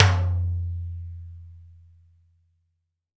Index of /90_sSampleCDs/AKAI S6000 CD-ROM - Volume 5/Cuba2/TIMBALES_2